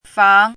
fáng
fang2.mp3